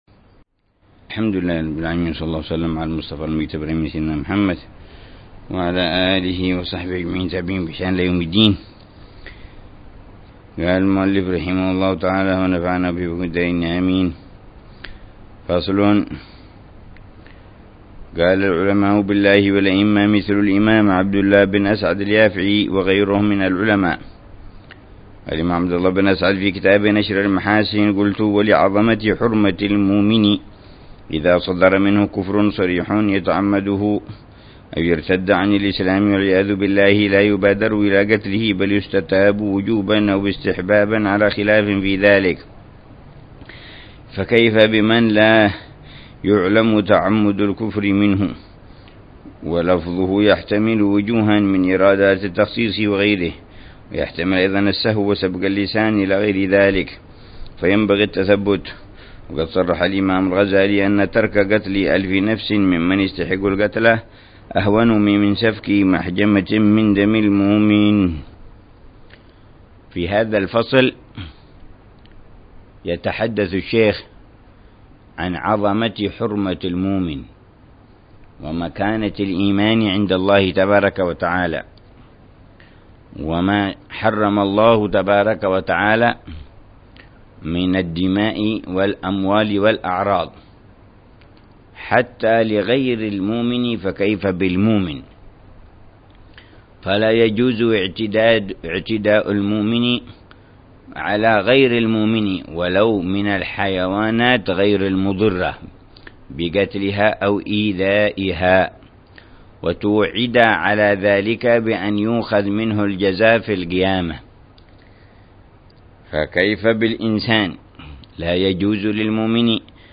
درس أسبوعي يلقيه الحبيب عمر بن حفيظ في كتاب الكبريت الأحمر للإمام عبد الله بن أبي بكر العيدروس يتحدث عن مسائل مهمة في تزكية النفس وإصلاح القلب